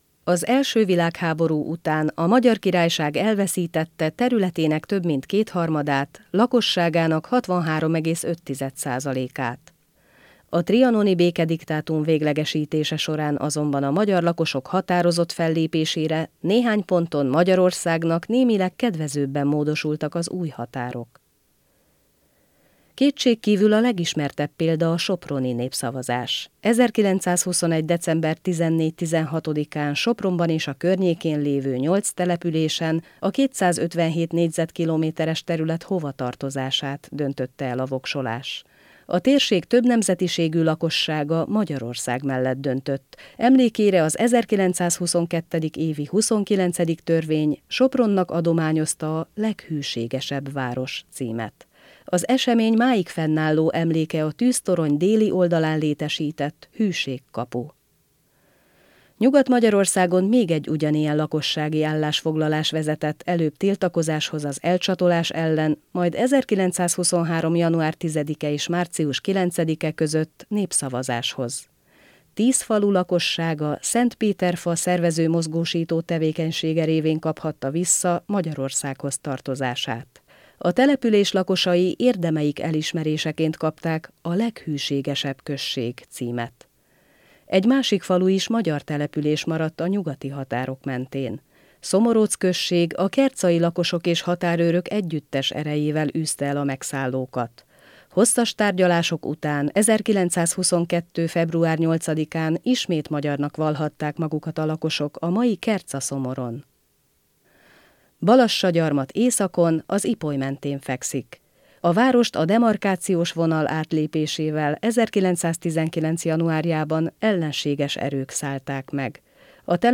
Tollbamondás 2022.